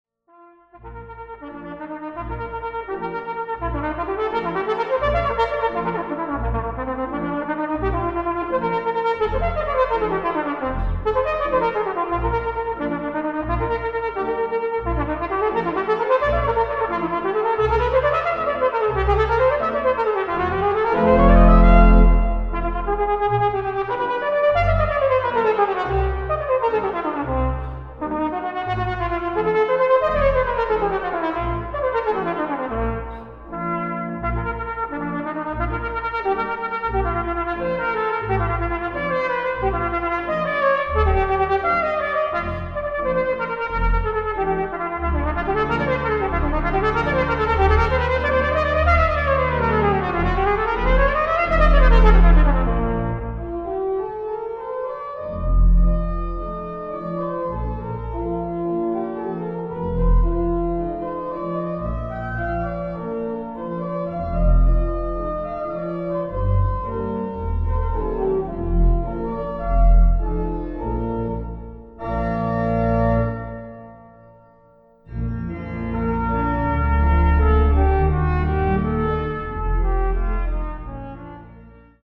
Australian classical music